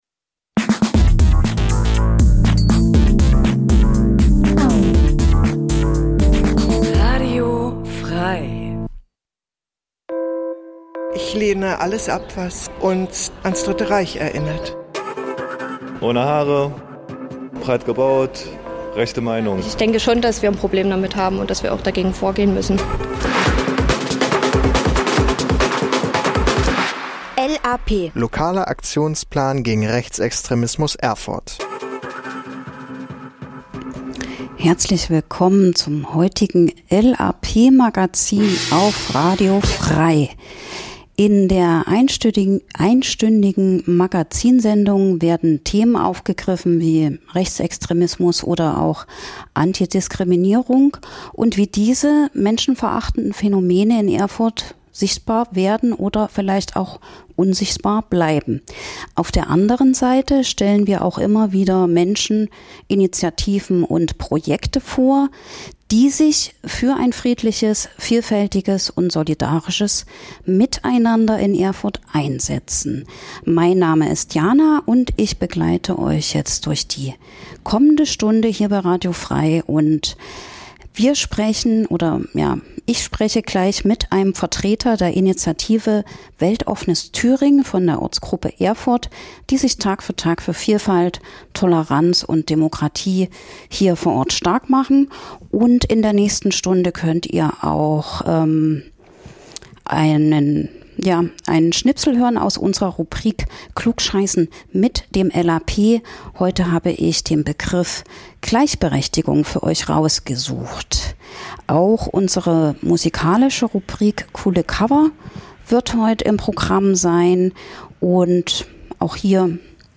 In der Sendung h�rt ihr verschiedene Beitr�ge rund um Demokratie und gegen Rechts, z. B. - Interviews zu aktuellen Themen - Veranstaltungshinweise - Musikrubrik "Coole Cover" --- Die Sendung l�uft jeden zweiten Mittwoch 11-12 Uhr (Wiederholung: Donnerstag 20 Uhr) und informiert �ber Themen, Projekte und Termine gegen Rechts in Erfurt und Umgebung.